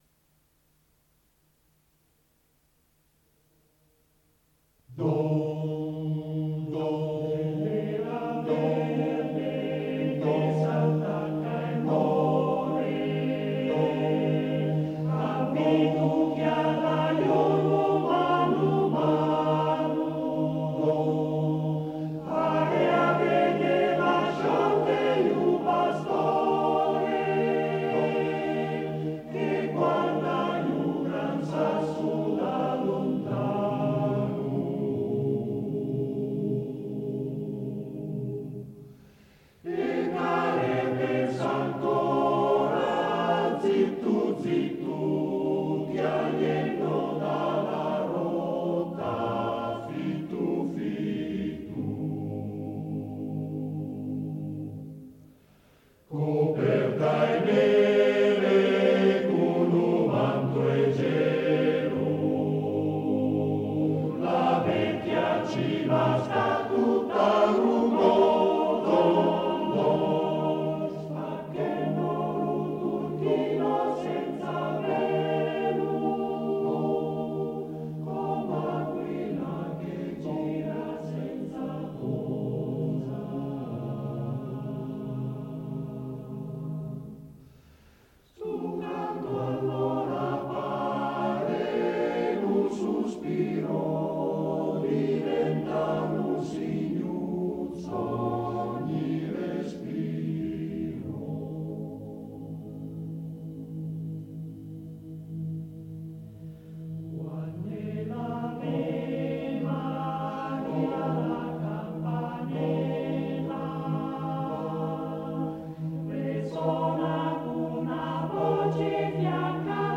Arrangiatore: Allia, Mario
Esecutore: Coro CAI Uget